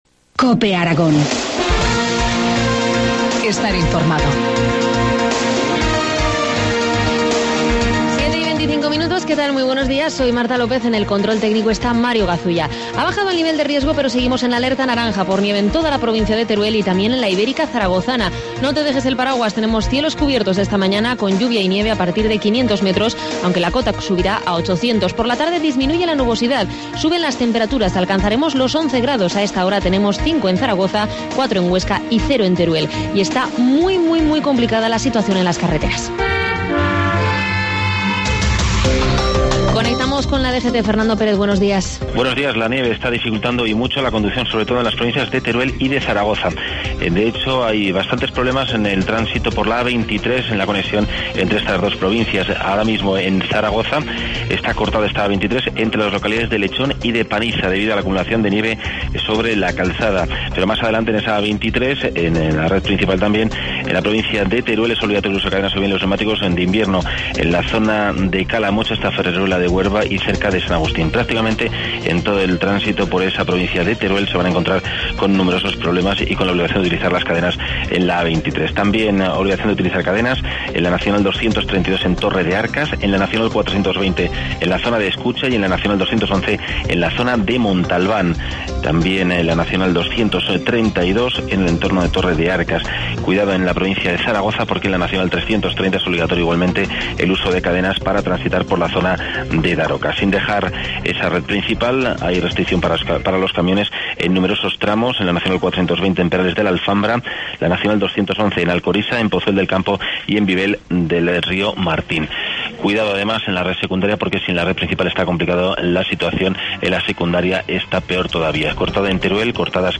Informativo matinal, viernes 1 de marzo, 7.25 horas